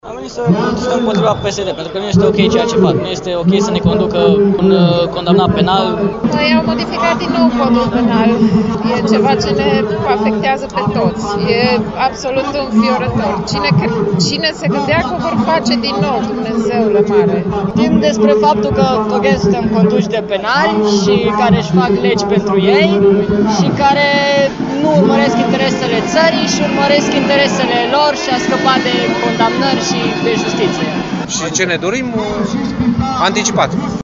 FOTO/ AUDIO/ Protest în Piața Victoriei din Timișoara
Voxuri-protestatari.mp3